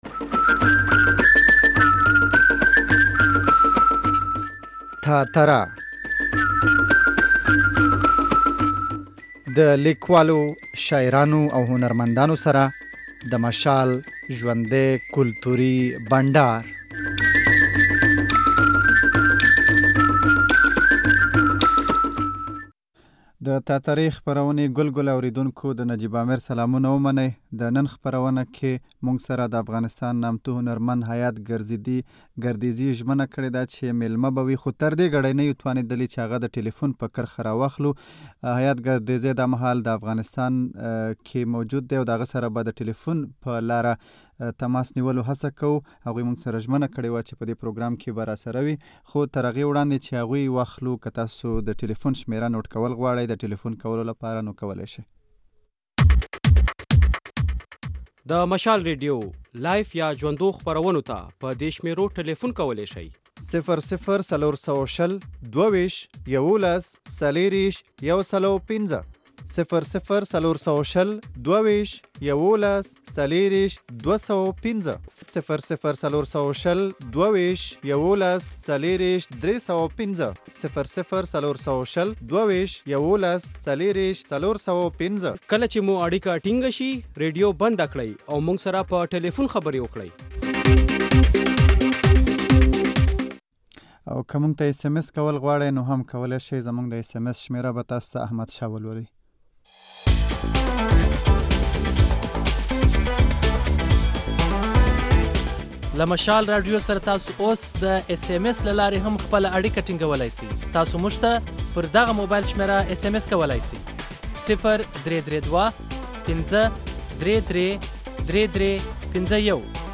دتاترې خپرونې په دې خپرونه کې مو میلمه دافغانستان پیژندل شوی هنرمند ( حیات ګردیزی ) دی ،نوموړی دهغو هنرمندانو په لیکه کې ولاړ دی چې دافغانستان موسیقۍ ته یې ډیره وده ورکړيده او دهنر شهرت یې په پښتنوخوا او نورو ټولو هغو سیمو کې خور دی .